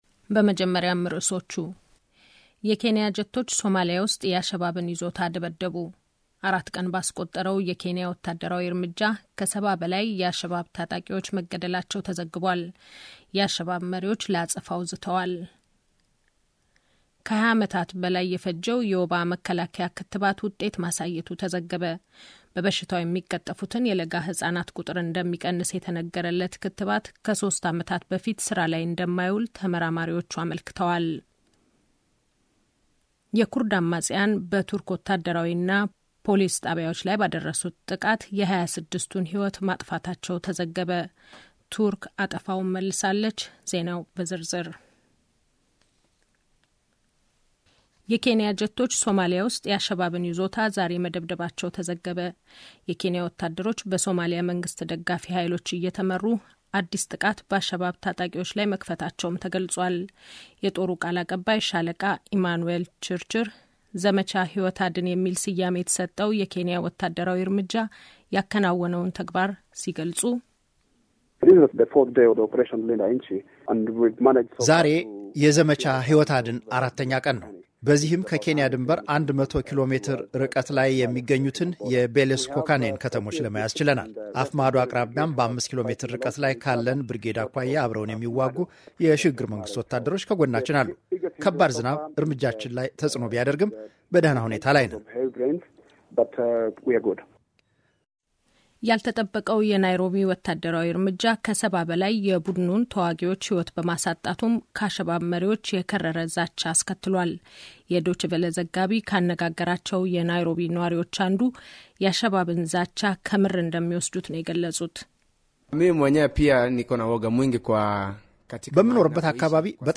ጀርመን ራዲዮ ዜናዎች – Oct. 19, 2011